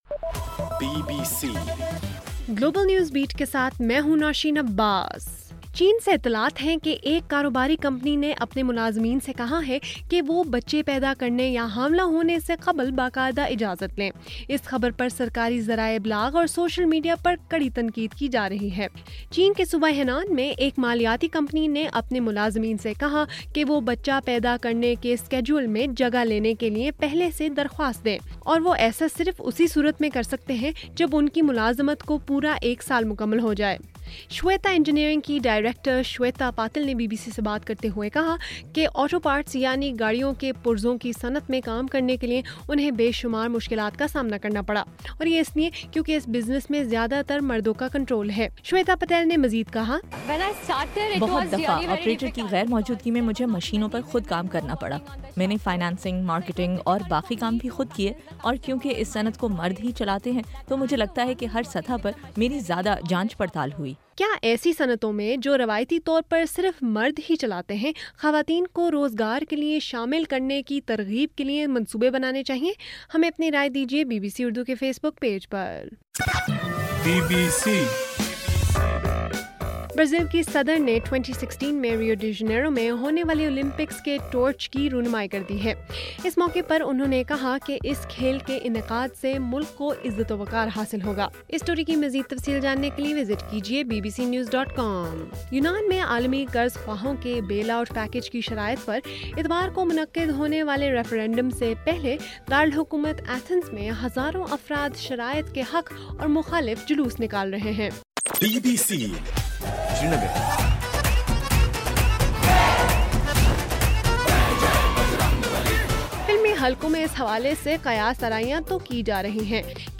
جولائی 4: رات 9 بجے کا گلوبل نیوز بیٹ بُلیٹن